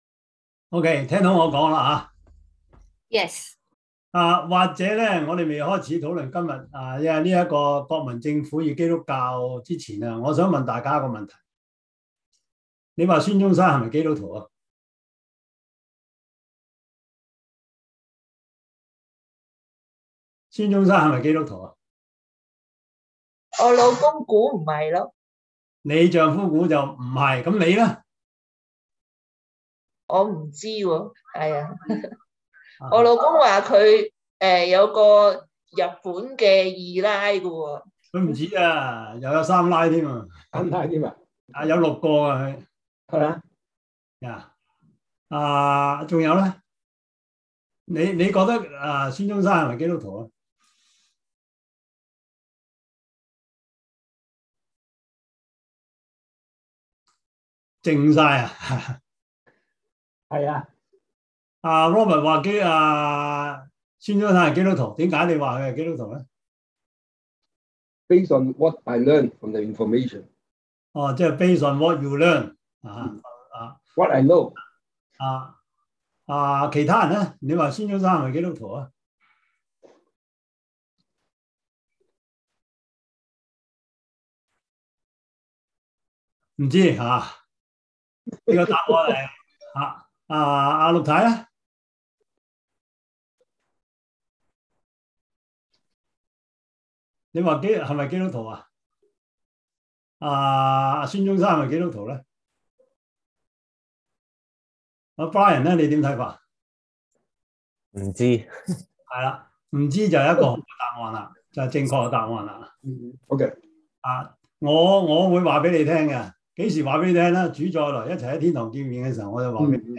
Service Type: 中文主日學